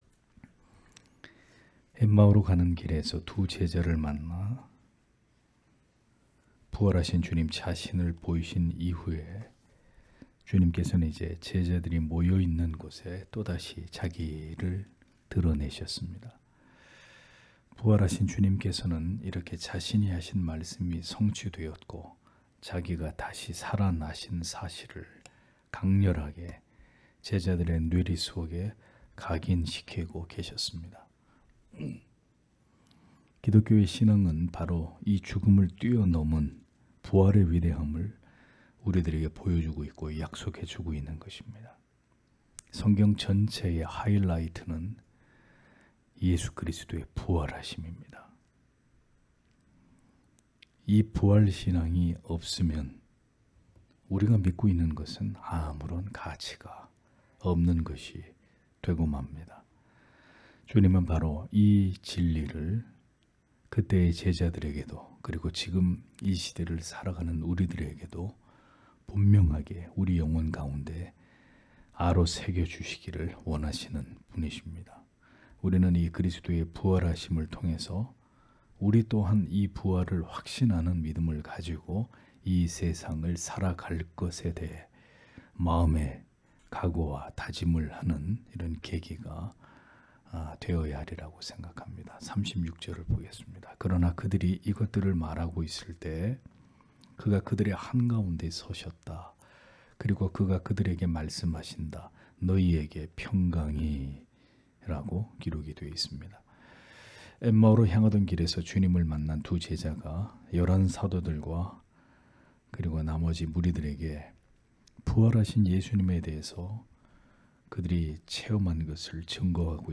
금요기도회 - [누가복음 강해 186] '너희에게 평강이 있을찌어다 (눅 24장 36- 43절)